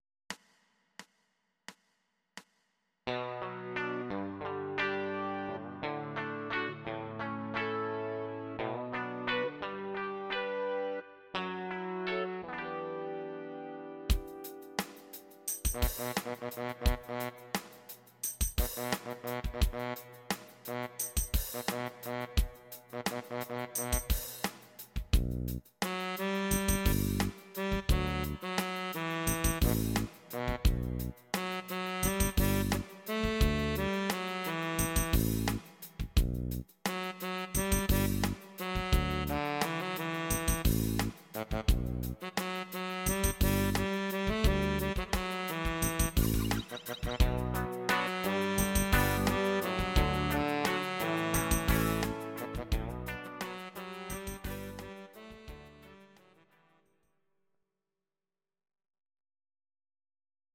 These are MP3 versions of our MIDI file catalogue.
Your-Mix: Rock (2970)